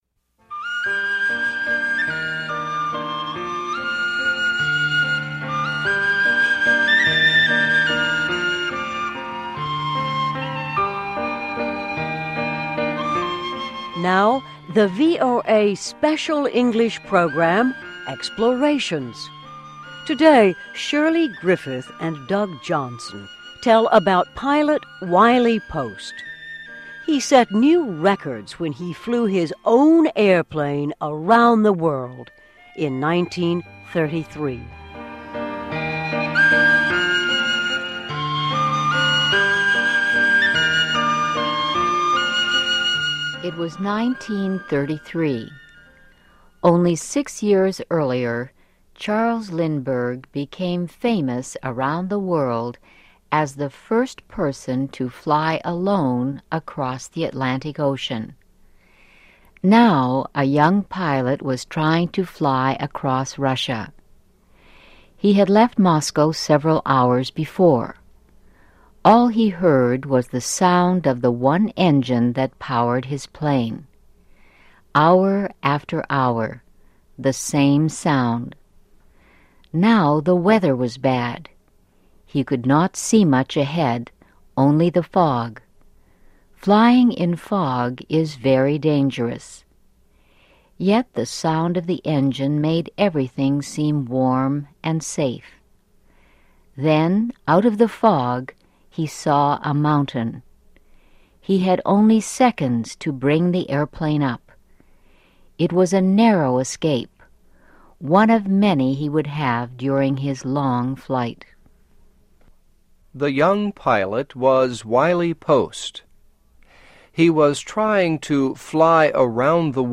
Now the VOA Special English program, Explorations.